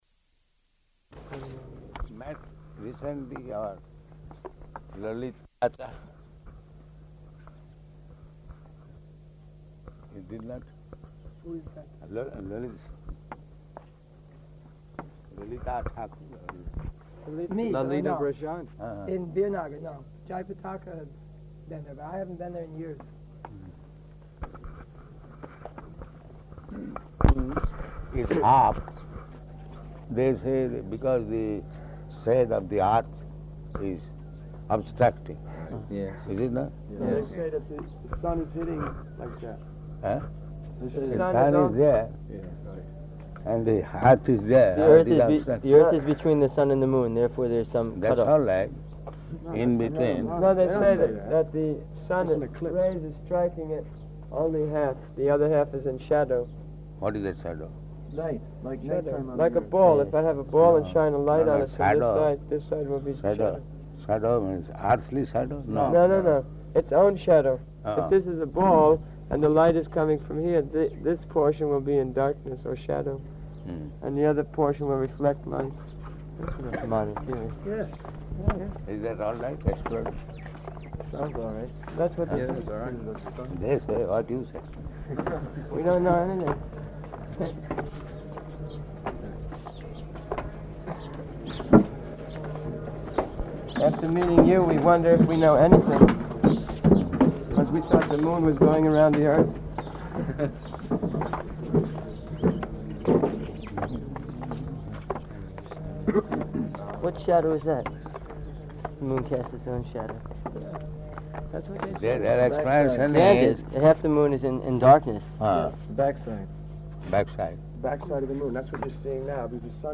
-- Type: Walk Dated: March 21st 1976 Location: Māyāpur Audio file